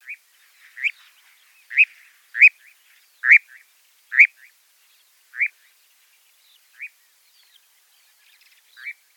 Звуки козодоя
Издает звуки